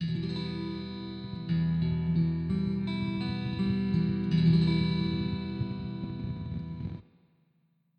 Schon hat man einen typischen Akustikgitarren Tonabnehmer in einem der besten Plätze für die Abnahme von Vibrationen montiert.
Ich denke, wenn man es nicht weiss, geht das auch als Aufnahme einer Akustikgitarre durch (auch wenn der Ton insgesamt durch den solid body natürlich heller ist - das könnte man aber sicher mit ein wenig bass-boost am Verstärker ändern) Zwischenstand Umbauprojekt der Hohner Revelation Orodruin:
PS: Falls jemand ein komplettes ATX Pickguard inkl Elektronik braucht, ich will meines loswerden Anhänge akustiktonabnehmertest.mp3 94,9 KB · Aufrufe: 988